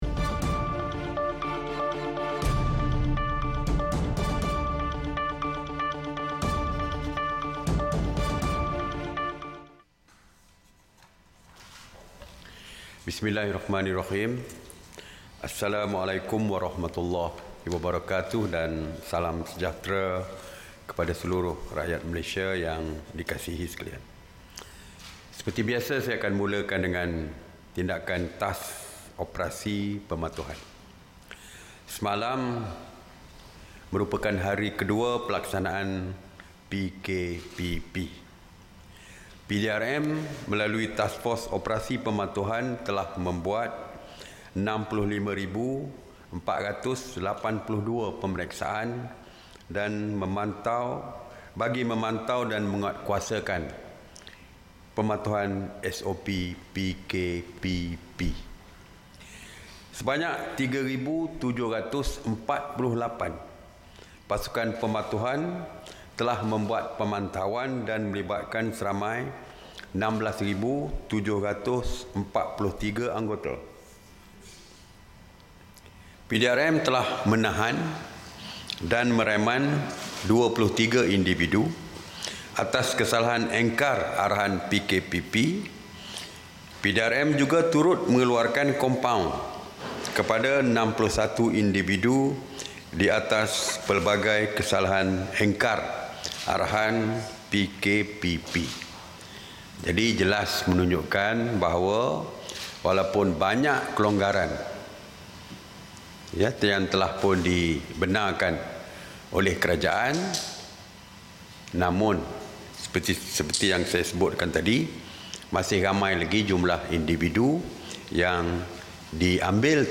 [SIDANG MEDIA] Perintah Kawalan Pergerakan Pemulihan (PKPP)
Ikuti sidang media khas berhubung Perintah Kawalan Pergerakan Pemulihan (PKPP), Menteri Kanan (Keselamatan), Datuk Seri Ismail Sabri Yaakob.